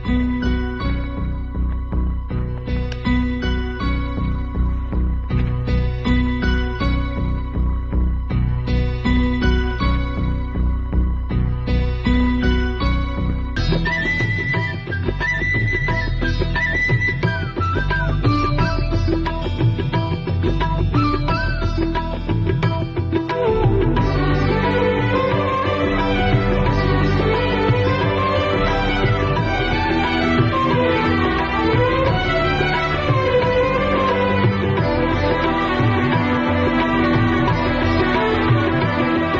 Enjoy the best background scores.